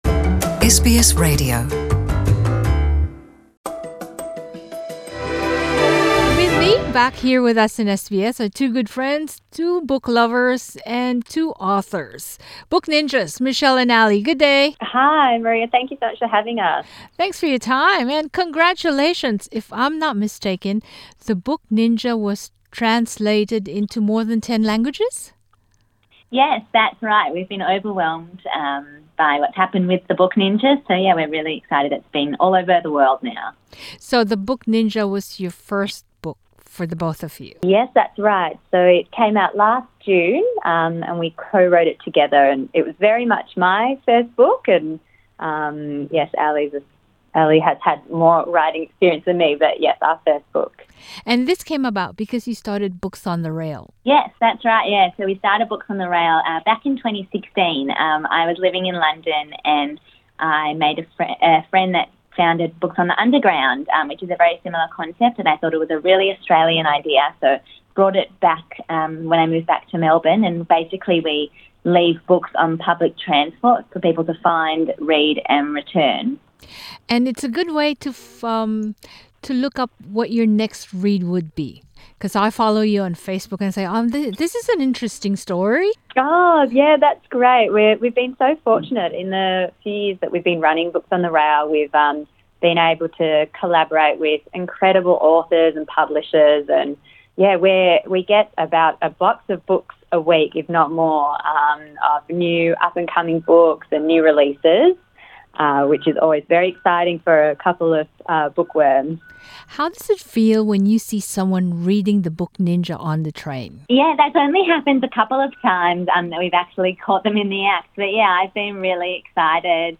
Nagbalik sa ating studio ang mga bookworm at kanilang ibinahagi ang mga kwento sa pagbuo ng kanilang aklat at ilan sa mga paboritong nabasang libro, Masayang pagbabasa sa inyo, pero bago yan pakinggan muna ang aming masayang usapan.